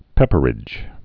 (pĕpər-ĭj)